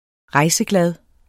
Udtale [ ˈʁɑjsəˌglad ]